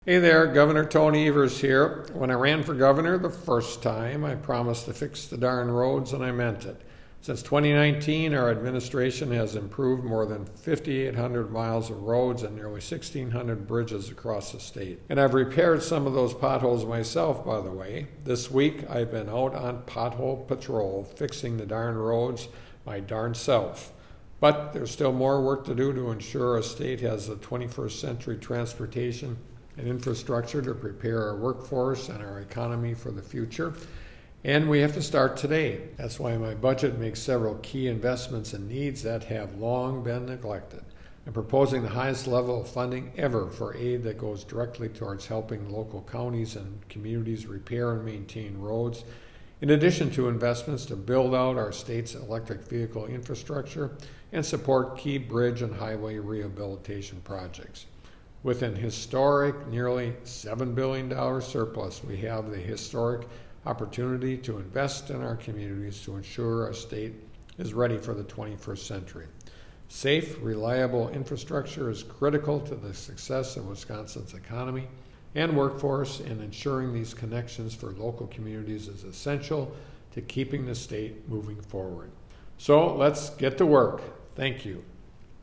MADISON — Gov. Tony Evers today delivered the Democratic Radio Address as he kicks off his annual Pothole Patrol tour to fix potholes across Wisconsin and highlight his plan to make historic investments in the state’s roads and infrastructure.